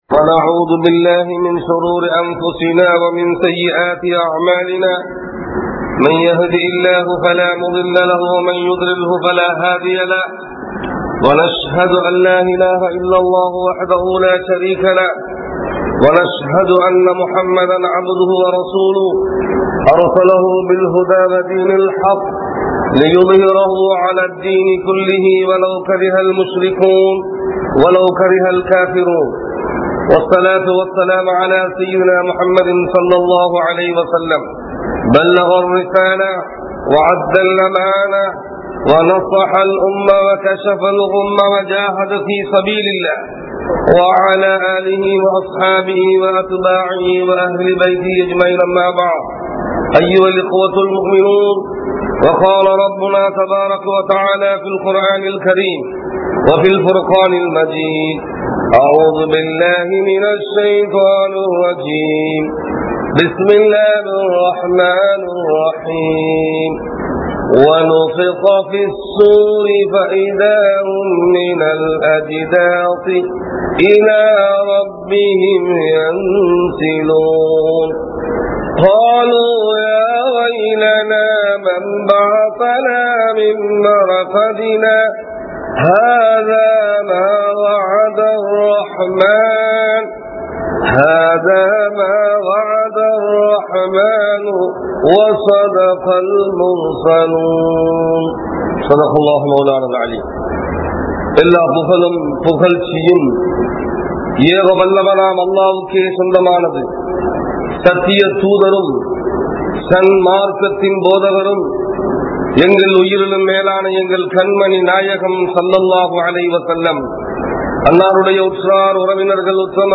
Qiyamath Naalin Adaiyaalangal (கியாமத் நாளின் அடையாளங்கள்) | Audio Bayans | All Ceylon Muslim Youth Community | Addalaichenai